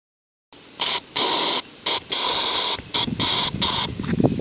喷罐
描述：气雾剂可以喷涂几次，可以是油漆罐还是驱虫剂。 （我为背景中的鸟叫声和风声道歉，在农村地区进行实地录音很困难。）